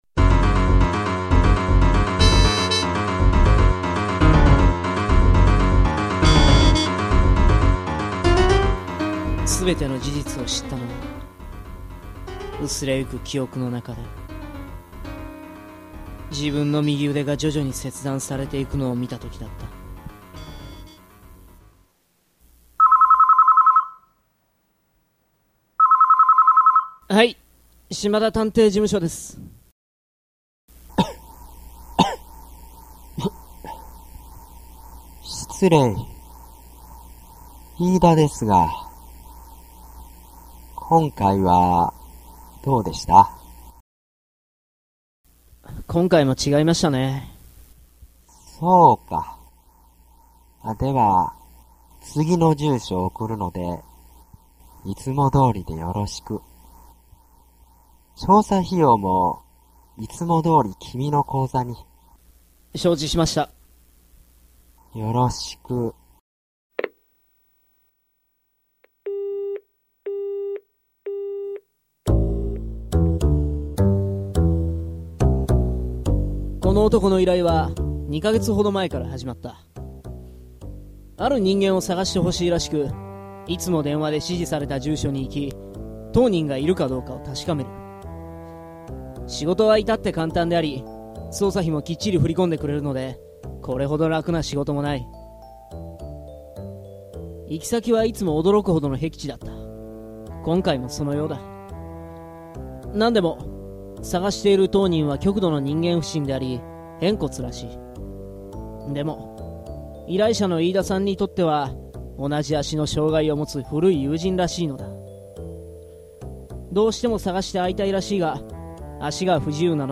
ラジオドラマ「格子のある部屋」
※このドラマは音声で聞くことが出来ます。